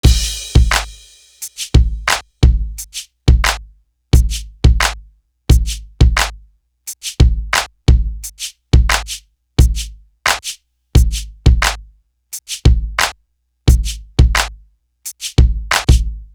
Bring You Light Drum.wav